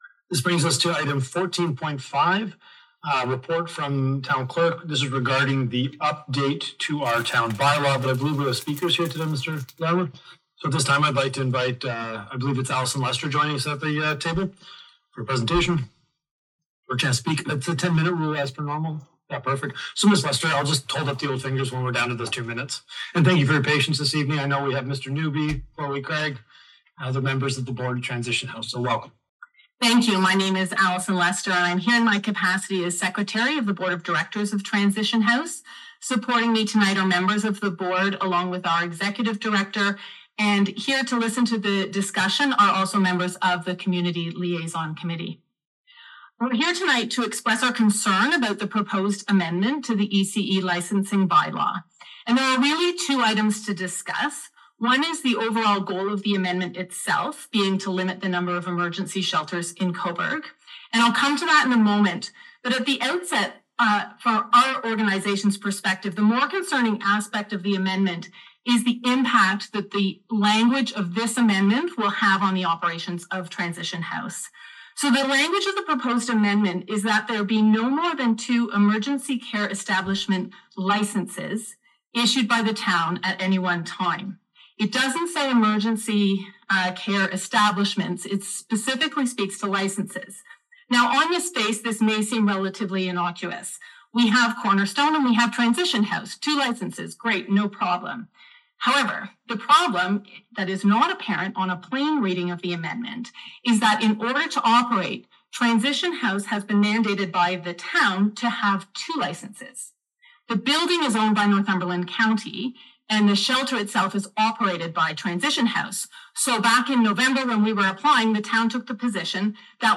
Cobourg council decided to limit the number of Emergency Care Establishments to two at its recent regular council meeting on April 30.
Mayor Lucas Cleveland and staff explained the town’s interpretation.
Listen to the presentation and the politician’s responses at the meeting.